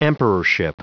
Prononciation du mot emperorship en anglais (fichier audio)
Prononciation du mot : emperorship